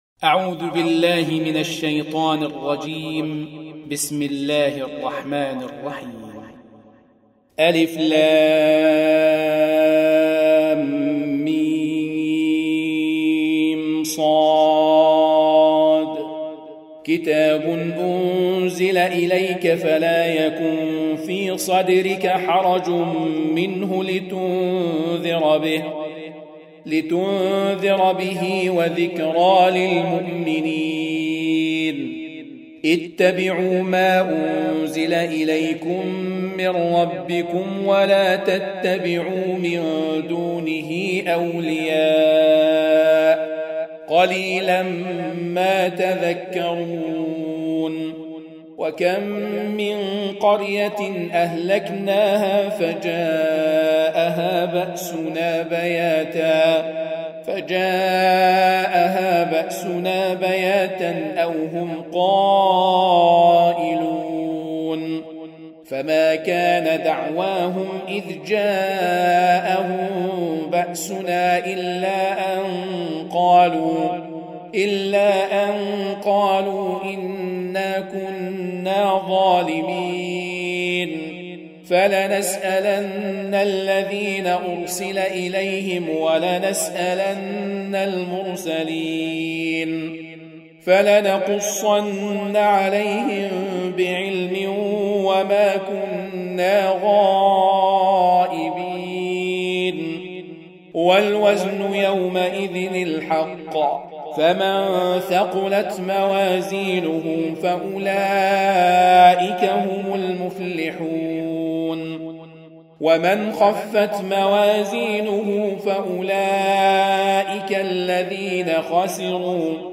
7. Surah Al-A'r�f سورة الأعراف Audio Quran Tarteel Recitation
Surah Repeating تكرار السورة Download Surah حمّل السورة Reciting Murattalah Audio for 7. Surah Al-A'r�f سورة الأعراف N.B *Surah Includes Al-Basmalah Reciters Sequents تتابع التلاوات Reciters Repeats تكرار التلاوات